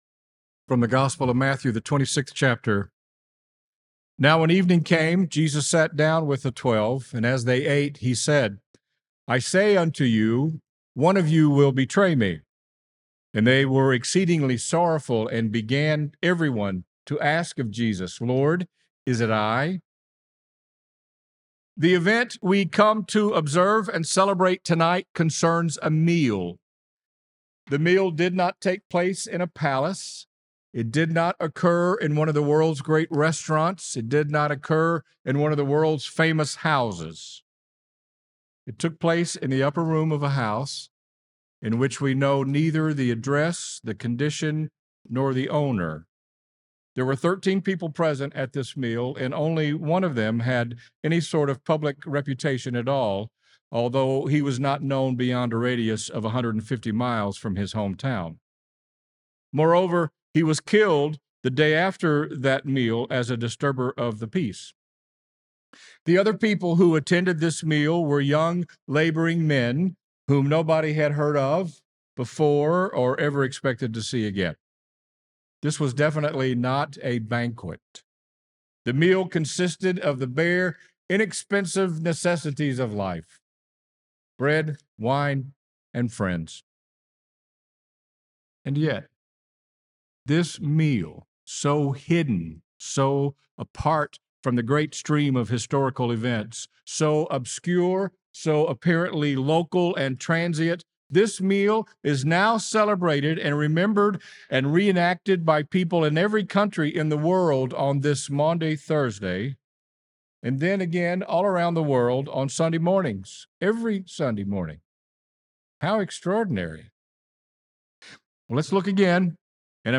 Faith Lutheran Church Knoxville Sermon Podcast 04.02.26 - Is It I, Lord?